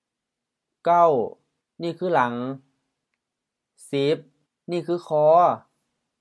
หลัง laŋ M หลัง 1. back, after
คอ khɔ: HR คอ 1. neck